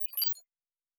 Data Calculating 1_5.wav